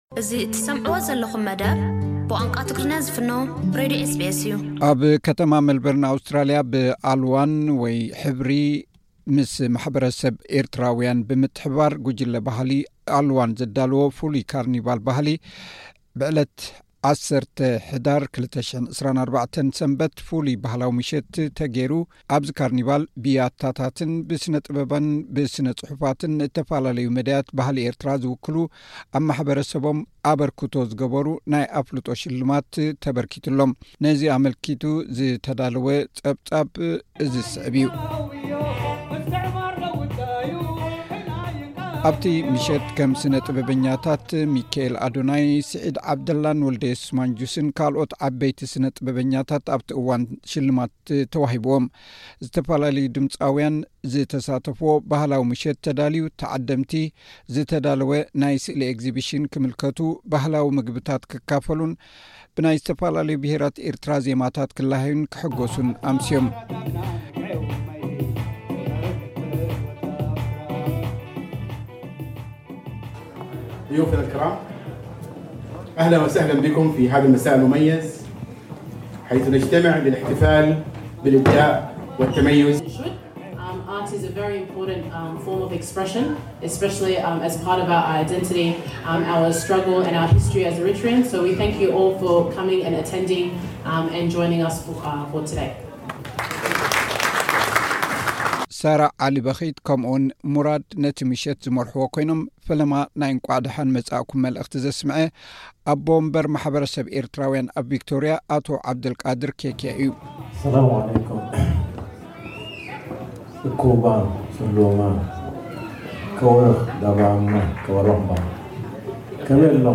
ነዚ ኣመልኪትና ምስ ኣወሃሃድቲ እቲ መደብ ቅድሚ እቲ ካርኒቫል ምጅማሩ ብዛዕባቲ መደብ ገሊጾሙልና ኣለዉ፡ ከነቕርቦ ኢና።